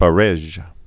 (bə-rĕzh)